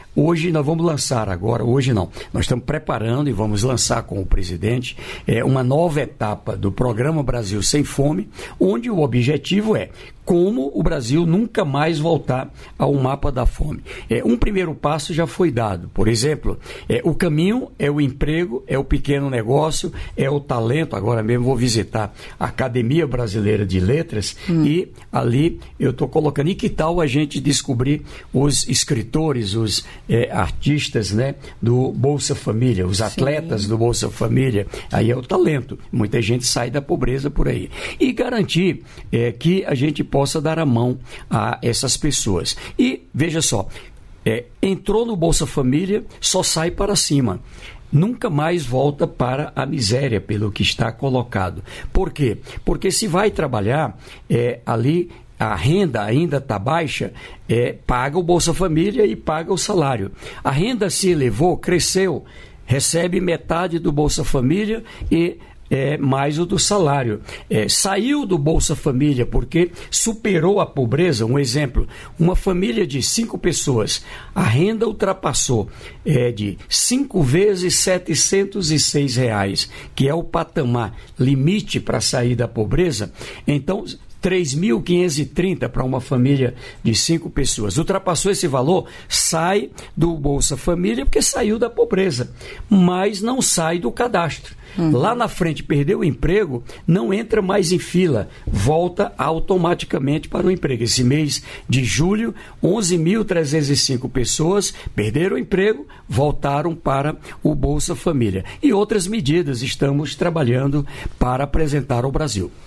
Trecho da participação do ministro do Desenvolvimento e Assistência Social, Família e Combate à Fome, Wellington Dias, no programa "Bom Dia, Ministro" desta quinta-feira (7), nos estúdios da EBC em Brasília (DF).